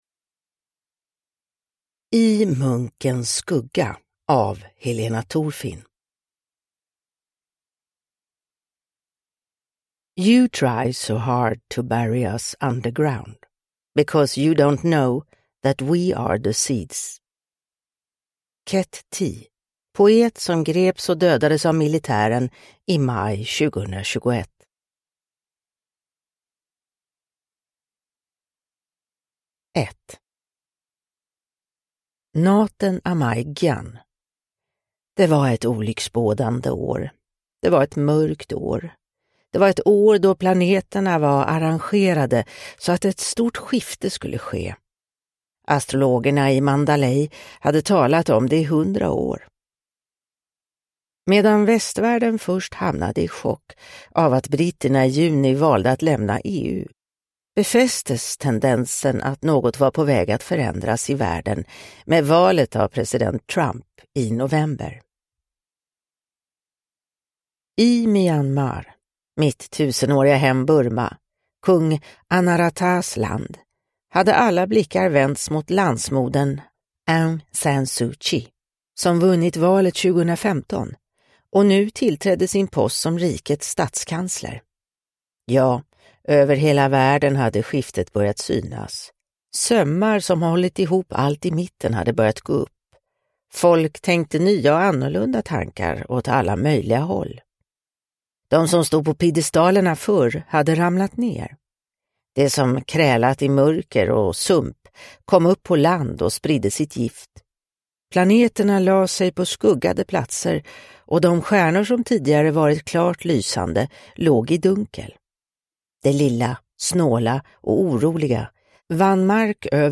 I munkens skugga – Ljudbok – Laddas ner